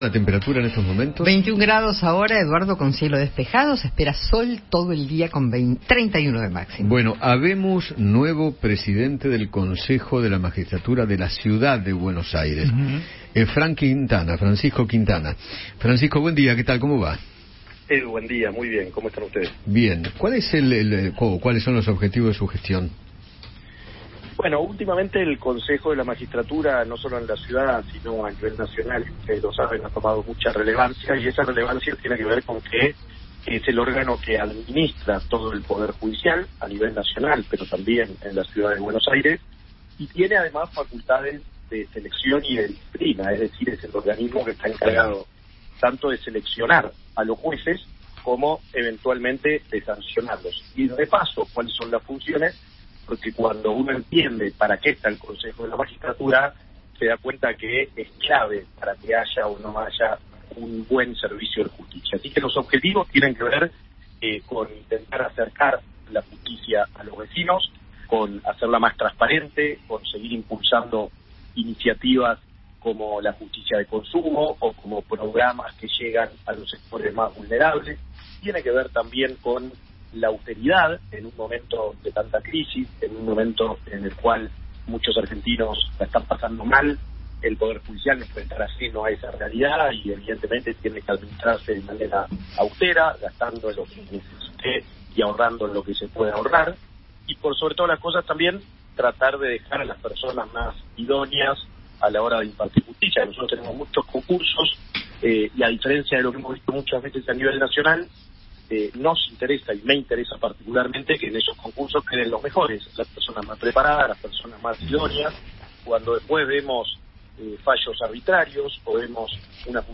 Francisco Quintana, flamante presidente del Consejo de la Magistratura de la Ciudad, conversó con Eduardo Feinmann sobre su nuevo rol en el órgano del Poder Ejecutivo y se refirió a la transferencia de delitos a CABA.